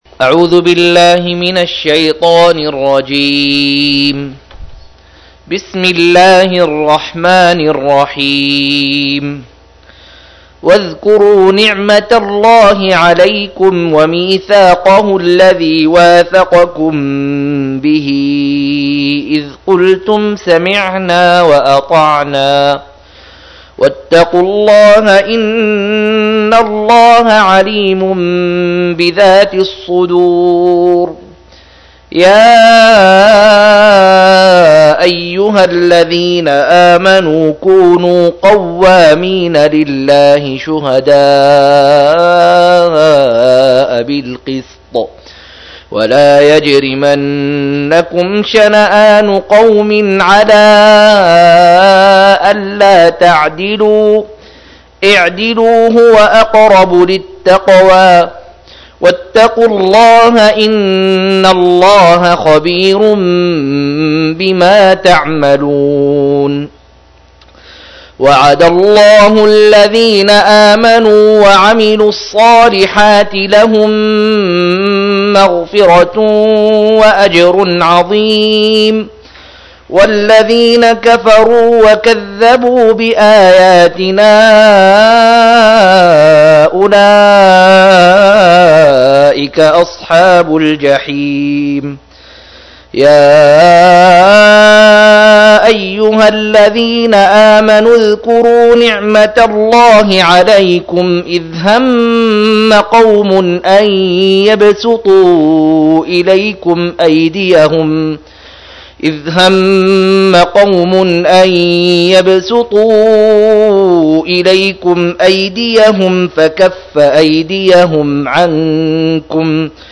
111- عمدة التفسير عن الحافظ ابن كثير رحمه الله للعلامة أحمد شاكر رحمه الله – قراءة وتعليق –